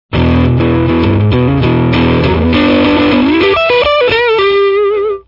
strat high gain
Tweedy - blues driver and treble booster
Varitone rotary switch acts as a select frequency mid-cut filter, which successfully emulates Fender scooped-mids sound.